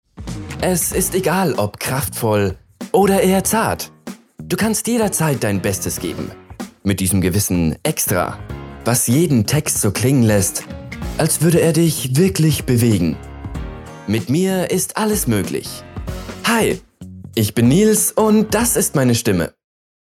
dunkel, sonor, souverän, markant
Jung (18-30)
Bayrisch
Commercial (Werbung), Comment (Kommentar), Tutorial